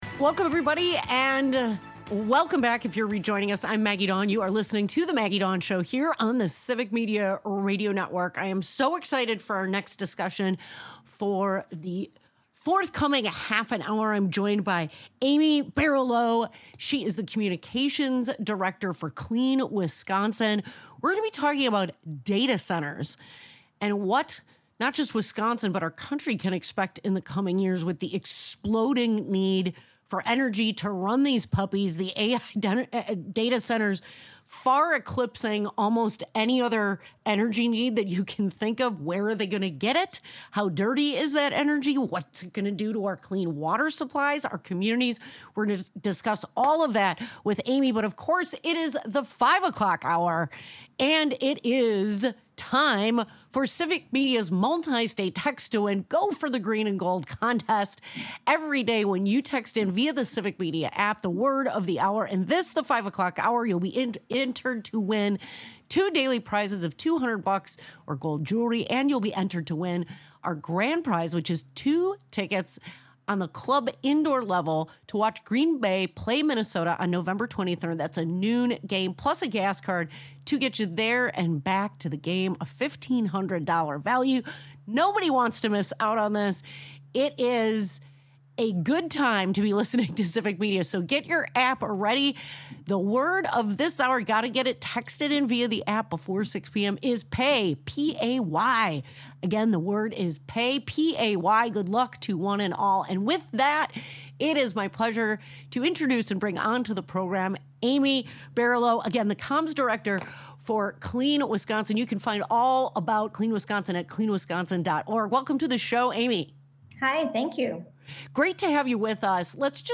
As these centers burgeon, they threaten to consume energy equivalent to millions of homes, raising questions about grid stability and environmental impacts. The discussion highlights the role of energy utilities and tech giants in escalating these demands, often at the expense of transparency and environmental considerations. Amidst this, the show also offers a fun respite with a civic media contest and lively discussions on sports and music, reminding listeners of Wisconsin's spirited community and culture.